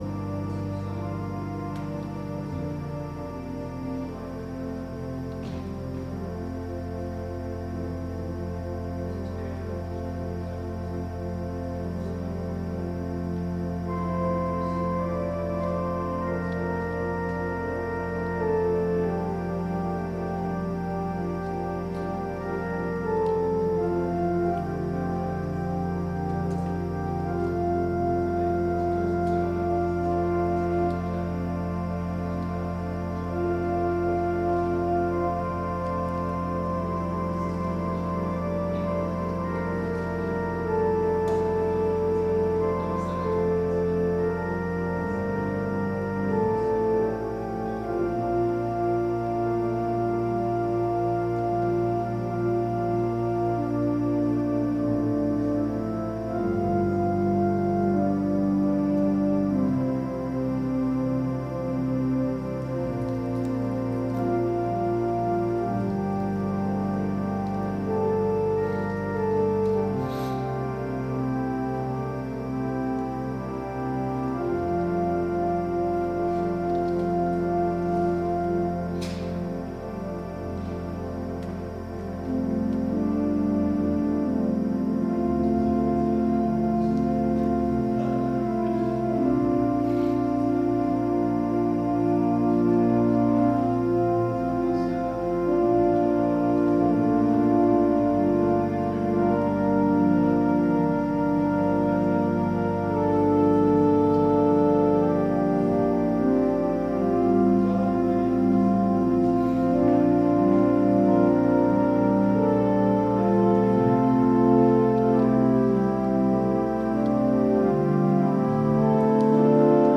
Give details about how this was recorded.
Podcast from Christ Church Cathedral Fredericton